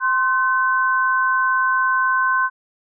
이명소리의 종류
이명소리 1
▶ 누르면 해당 이명소리가 들립니다.